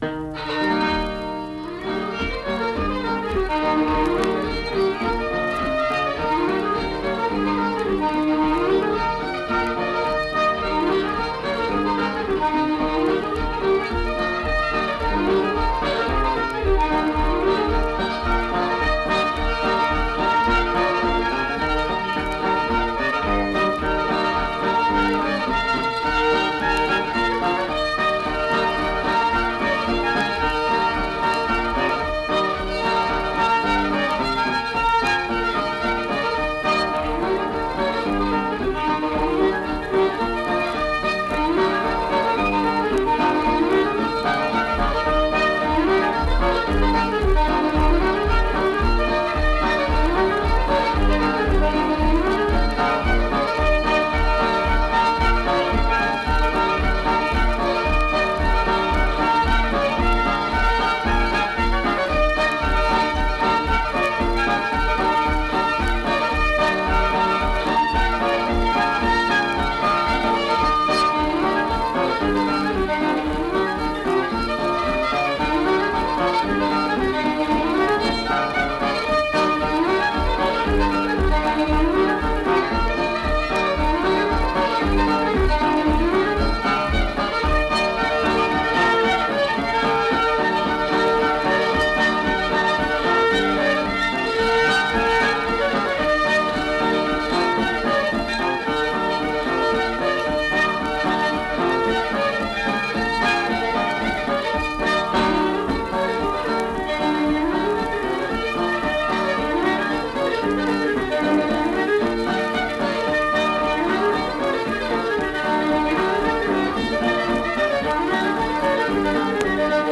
Accompanied guitar and unaccompanied fiddle music performance
Instrumental Music
Fiddle, Guitar